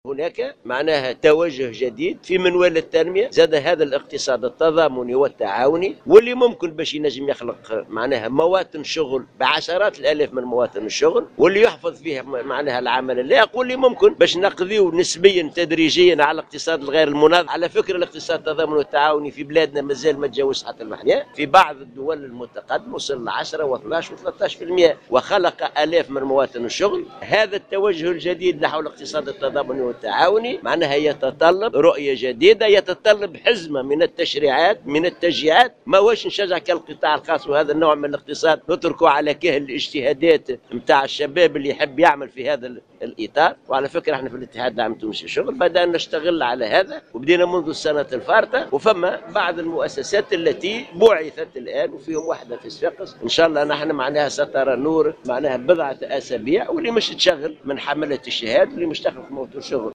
أكد الأمينُ العام للاتحّاد العام التونسي للشُغل حسين العبّاسي صباح اليوْم خلال اشرافه على افتتاح ورشات عمل الحوار الوطني للتشغيل إمكانية ضرورة ايلاء الأهمية اللازمة للإقتصاد التضامني التعاوني الذي من شأنه يوفر عشرات آلاف مواطن الشغل في تونس.